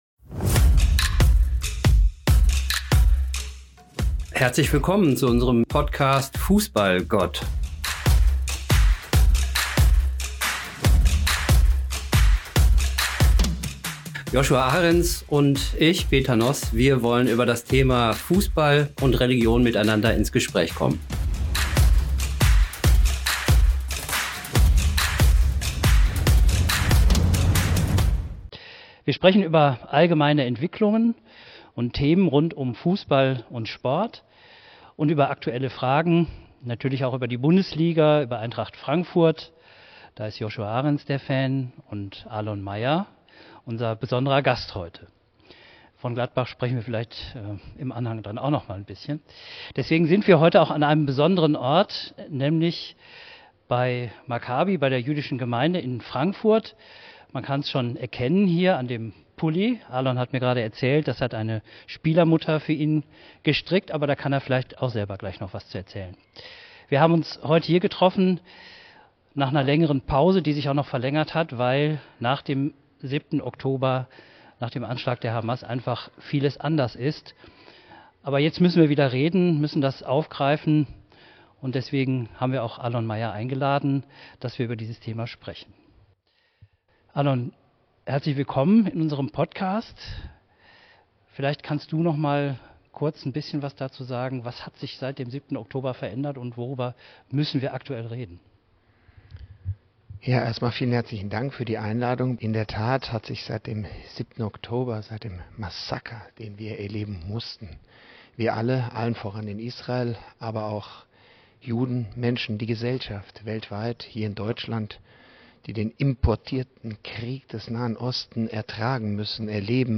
Wir haben ihn in der Jüdischen Gemeinde Frankfurt getroffen. Wir sprechen über Antisemitismus im Sport.